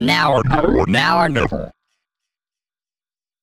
Techno / Voice
1 channel